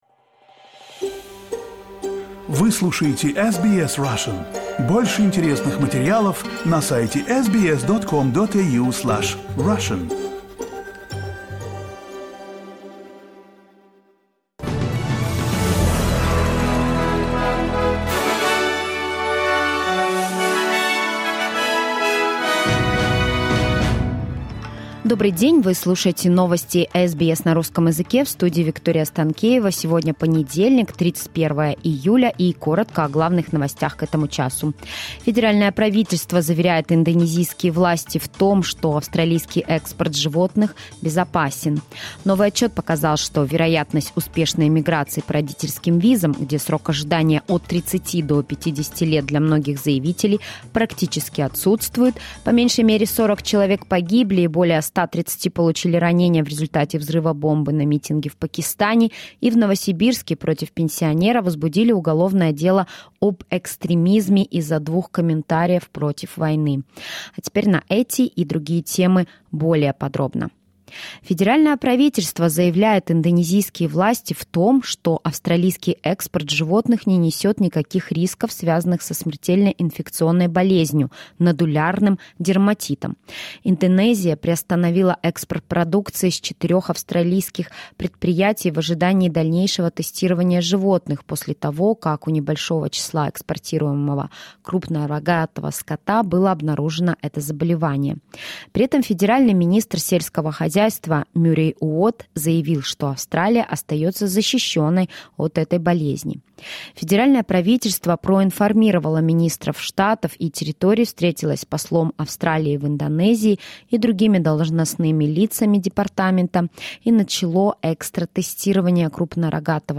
SBS news in Russian — 31.07.2023